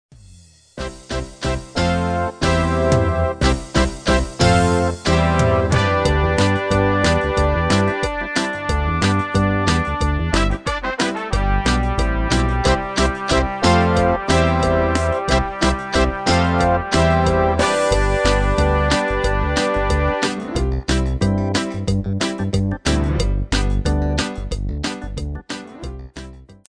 Demo/Koop midifile
U koopt een GM-Only midi-arrangement inclusief:
- Géén vocal harmony tracks
Demo's zijn eigen opnames van onze digitale arrangementen.